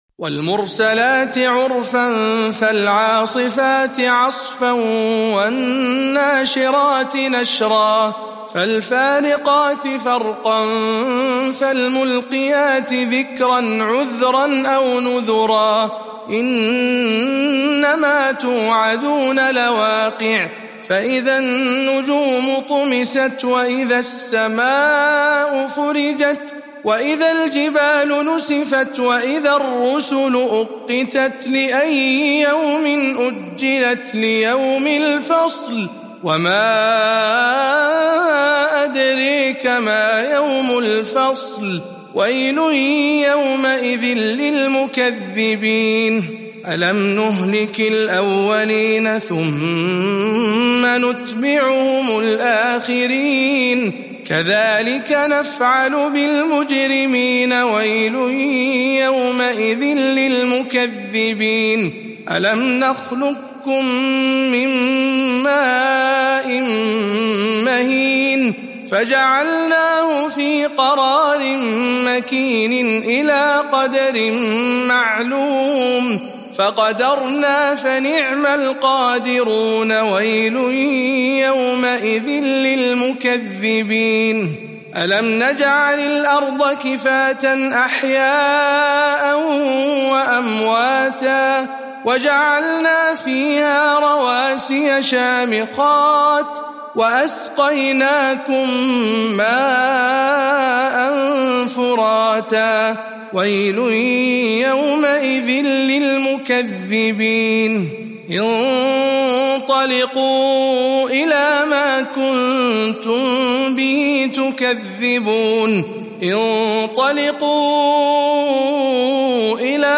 سورة المرسلات برواية شعبة عن عاصم